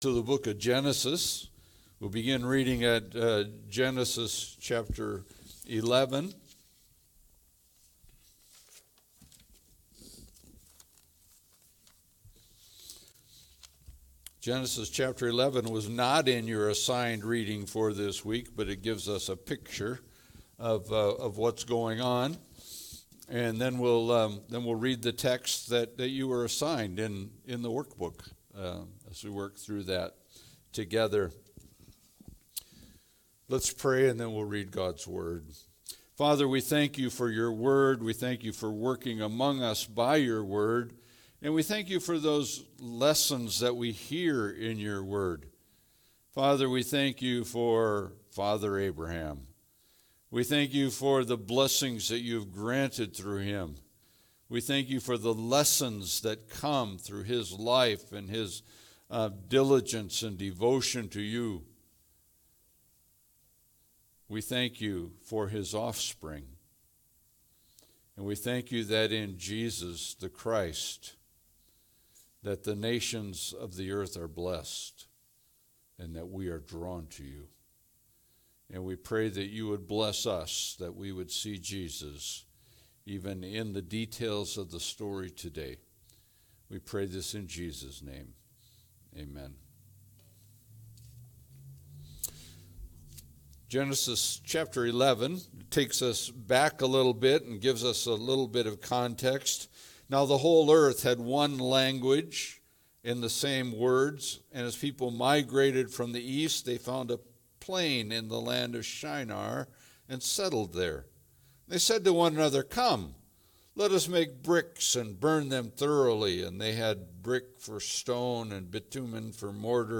Galatians 3:1-9 Service Type: Sunday Service « Walking With God Go…